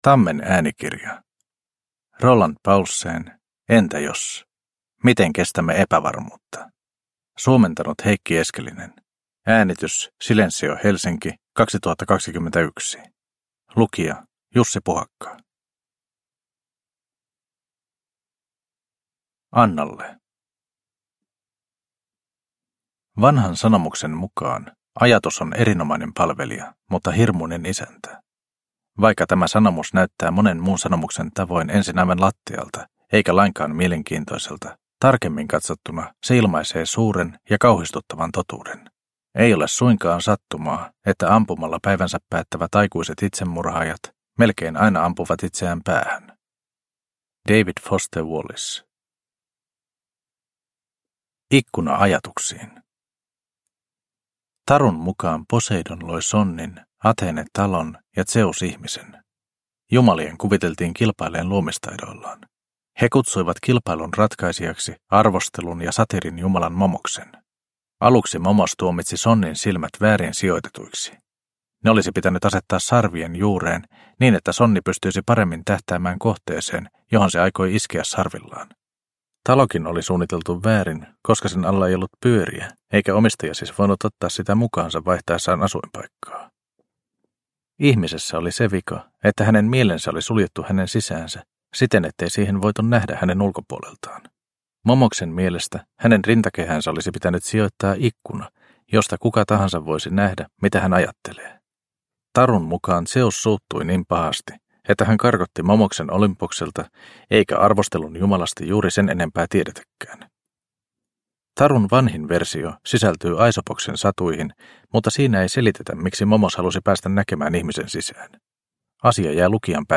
Entä jos? Miten kestämme epävarmuutta – Ljudbok – Laddas ner